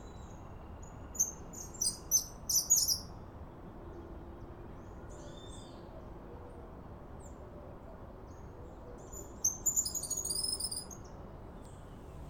Suiriri-cavaleiro (Machetornis rixosa)
Nome em Inglês: Cattle Tyrant
Localidade ou área protegida: Del Viso
Condição: Selvagem
Certeza: Observado, Gravado Vocal